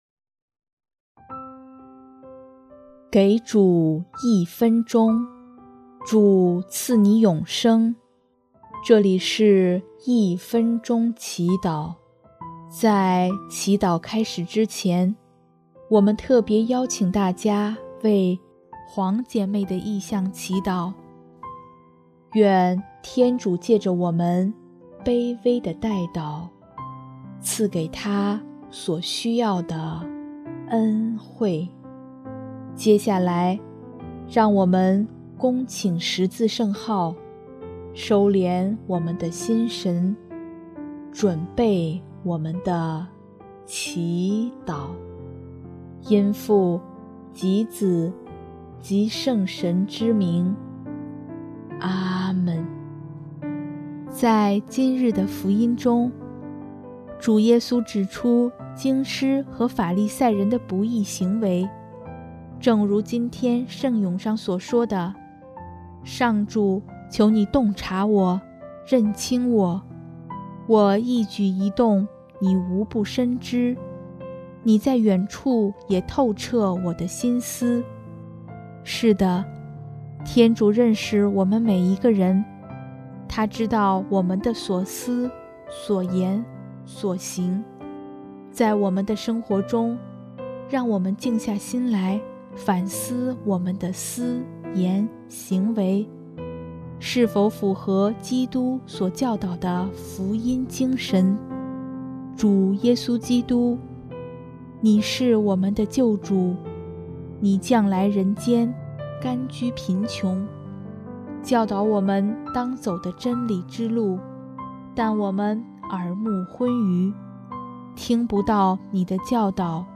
【一分钟祈祷】|8月26日 天主认识我们每一个人